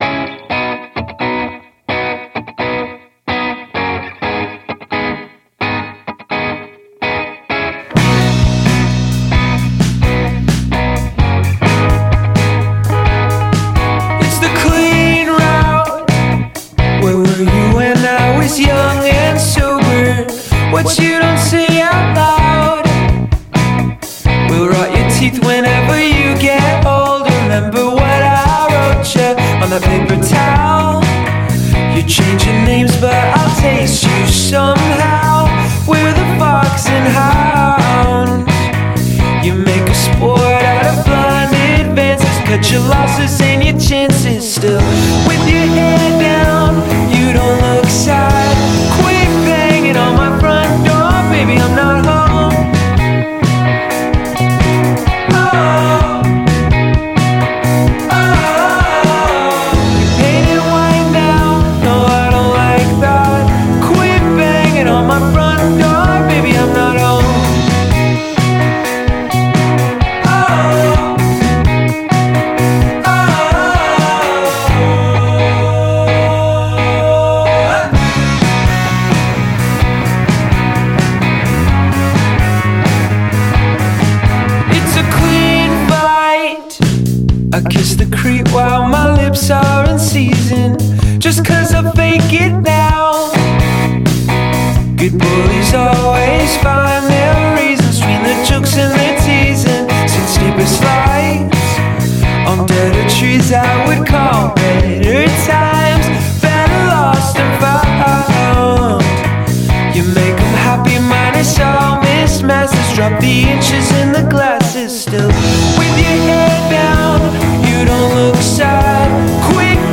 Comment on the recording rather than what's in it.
using the same room, same equipment